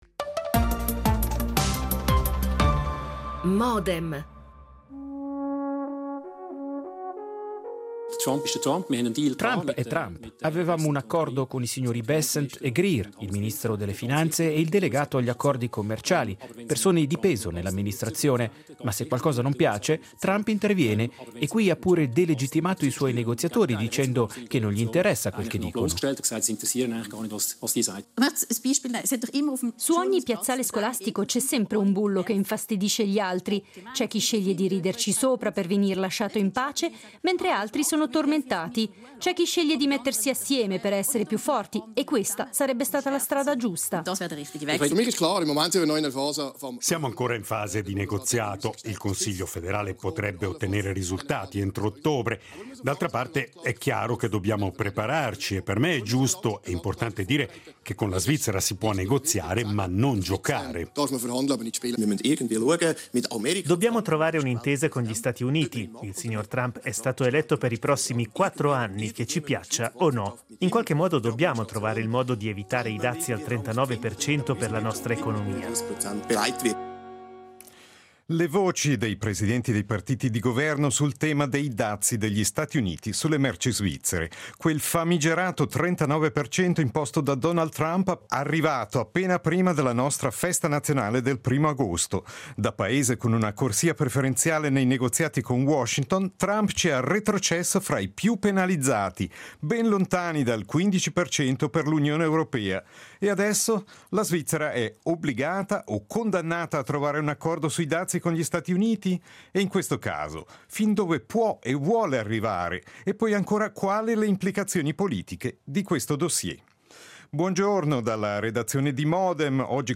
Intervista registrata con Cédric Wermuth, copresidente del PS svizzero
L'attualità approfondita, in diretta, tutte le mattine, da lunedì a venerdì